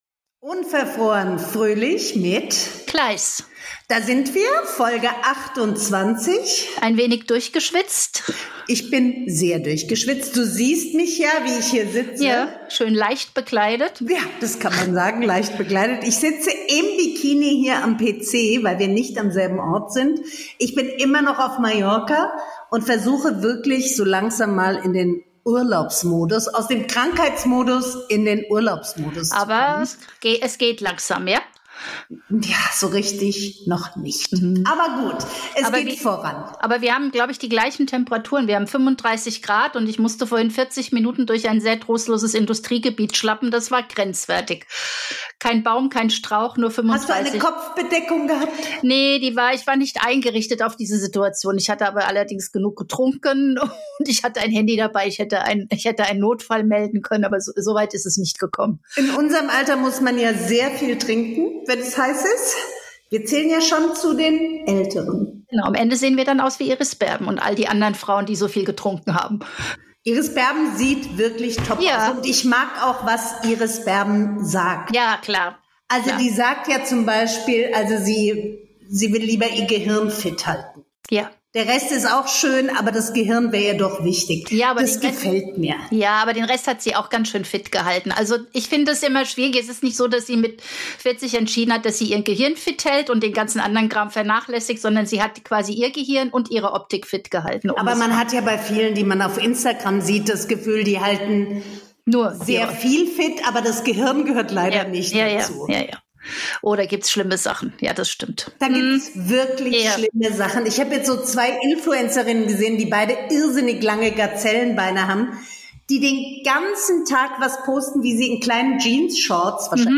die beiden Podcasterinnen